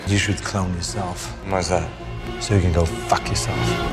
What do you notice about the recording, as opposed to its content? From The 6th Day (2000) — Adam Gibson